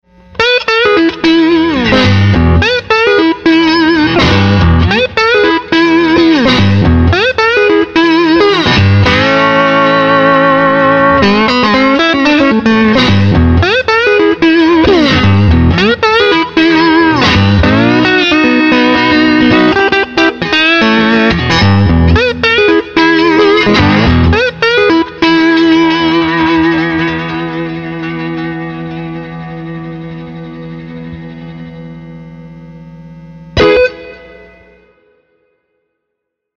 Here are 3 Nice clips of my  Total 60's Strat set in a Jeff Beck Stratocaster.
Neck. This was recorded through a Clark Piedmont (Tweed Bassman replica).